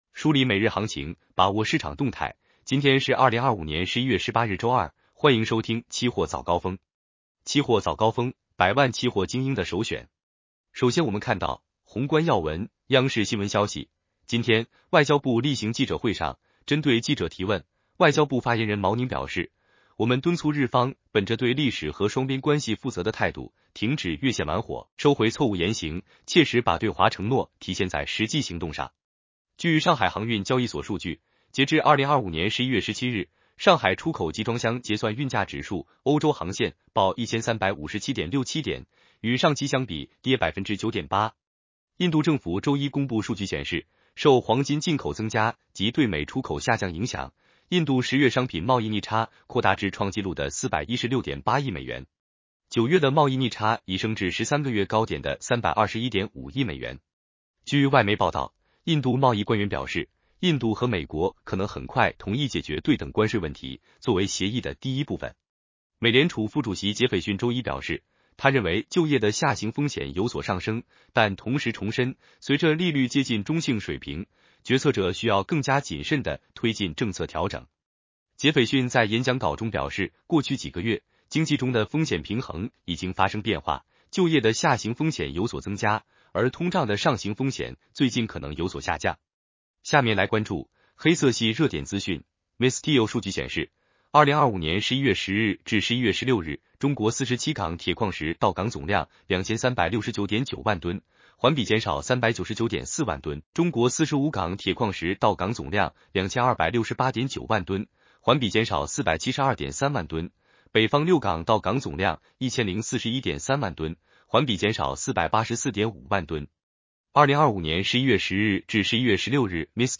期货早高峰-音频版 男生普通话版 下载mp3 热点导读 1.